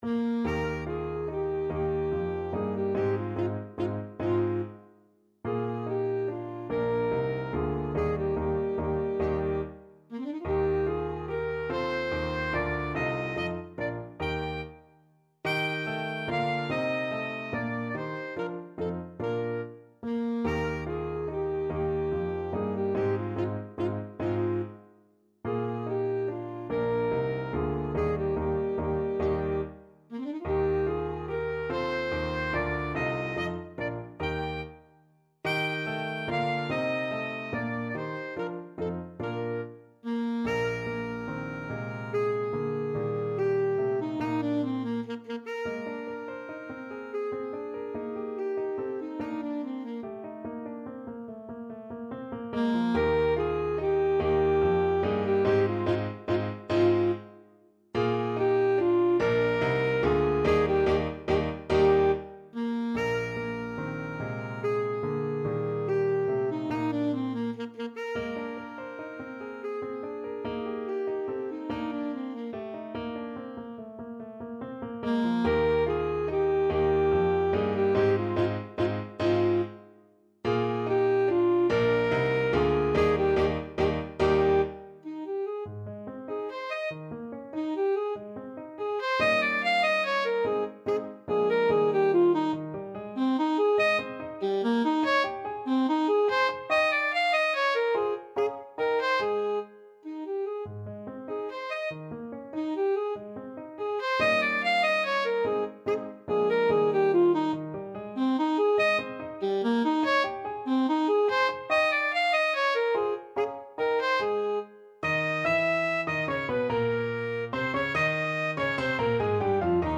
Alto Saxophone version
Alto Saxophone
3/4 (View more 3/4 Music)
Moderato =c.144
Classical (View more Classical Saxophone Music)